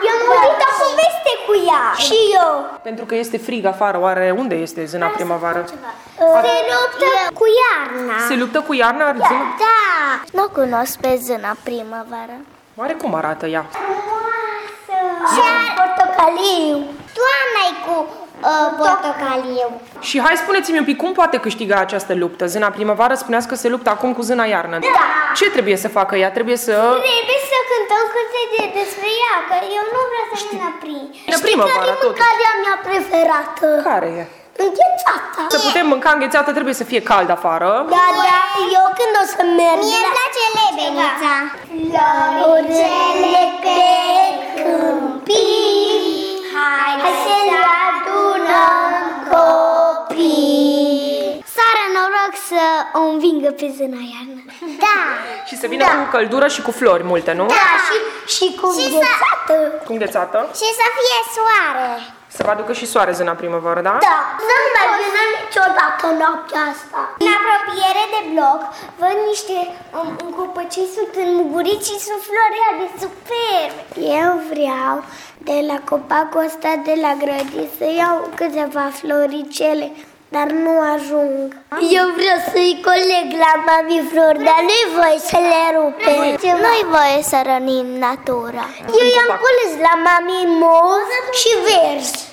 Copiii i-au cântat un cântecel, astfel ca zâna să câștige lupta cu iarna și frigul de afară să dispară.